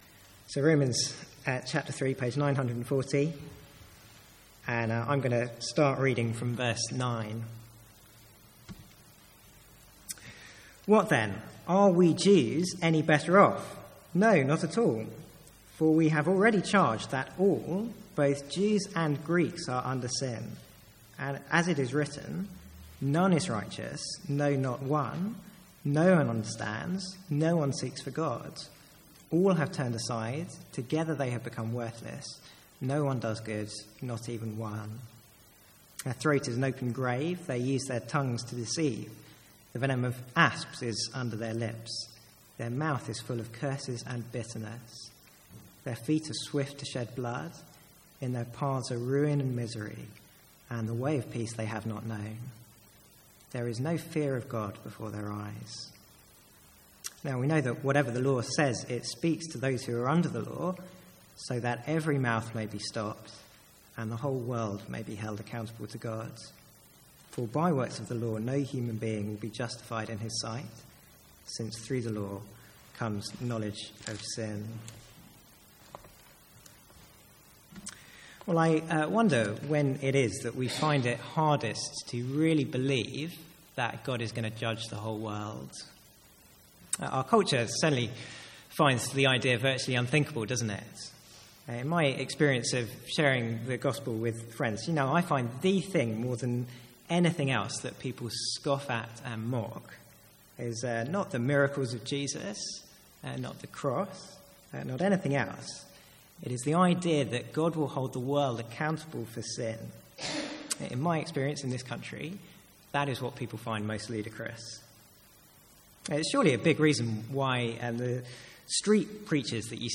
Sermons | St Andrews Free Church
From the Sunday evening series on the Five Points of Calvinism.